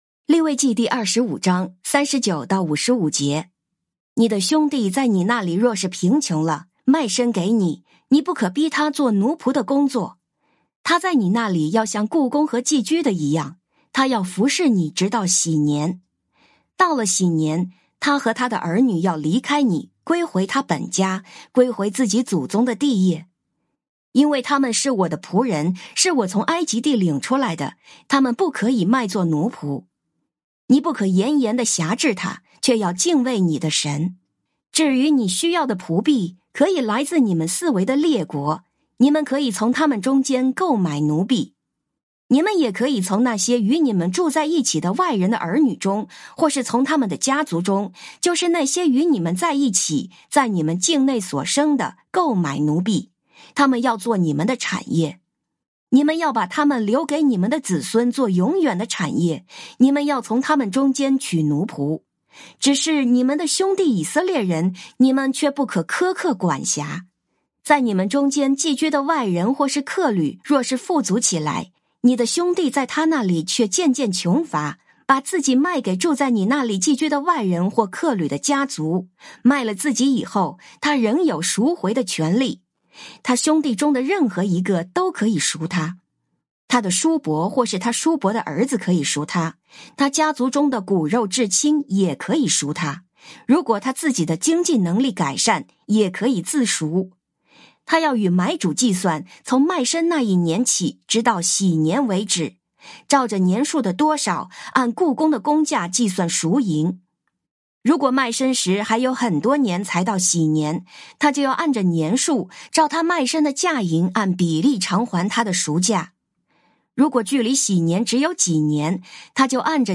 靈修分享：利25章39-55節「控制欲」